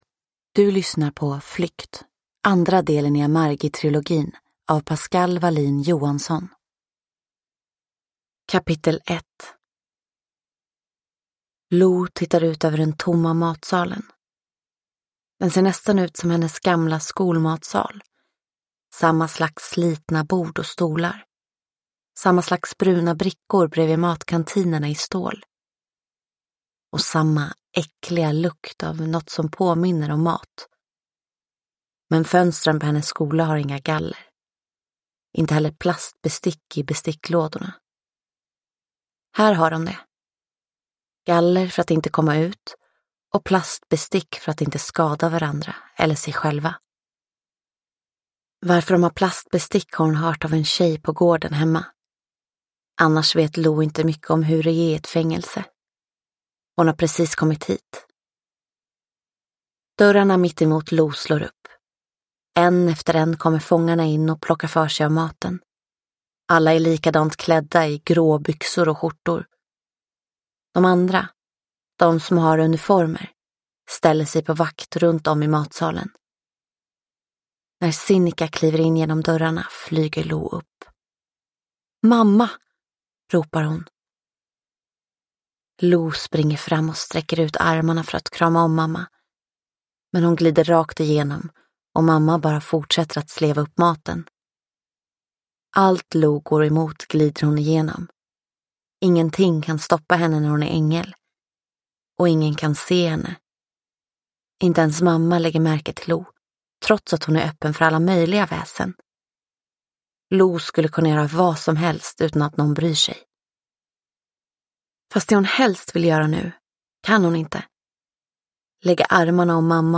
Flykt – Ljudbok – Laddas ner